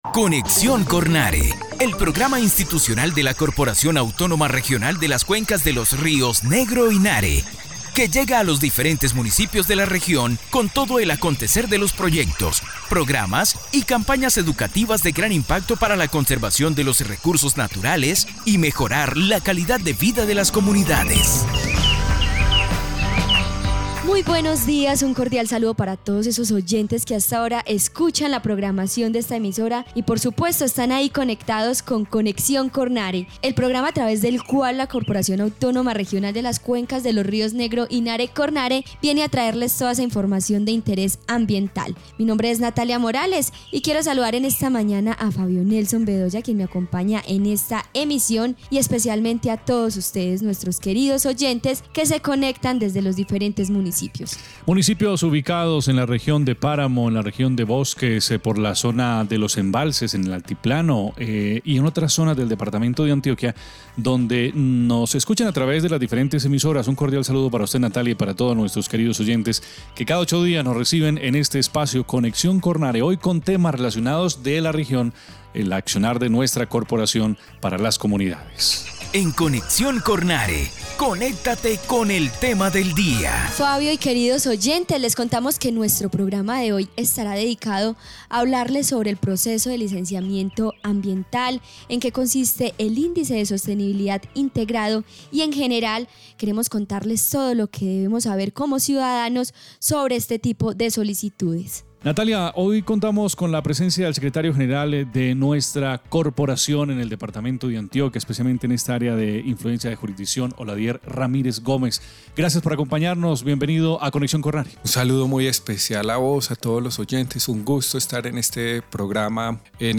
Programa de radio 2023